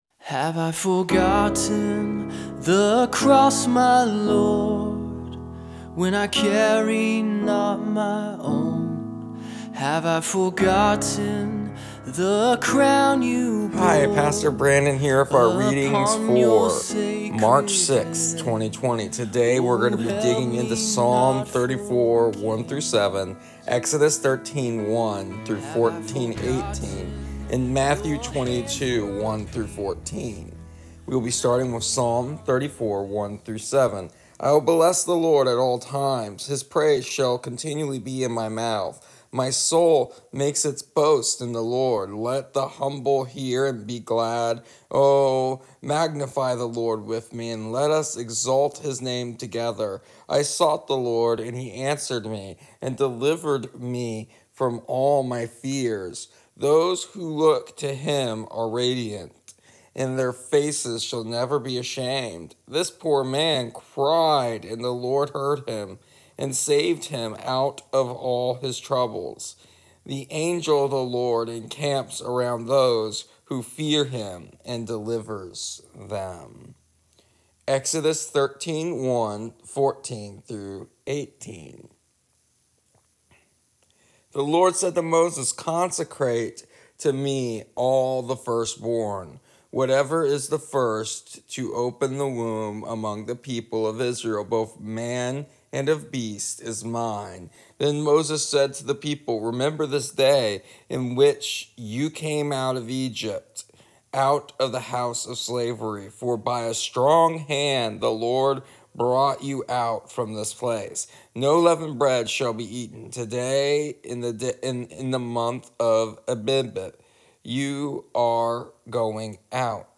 Here is our daily readings and audio devotional for March 6th. Today we talk about struggle and challenges and what God reminds us of when in those moments.